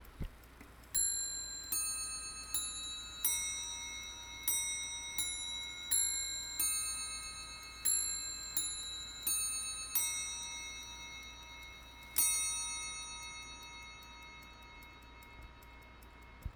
Мелодии
Тип механизма механический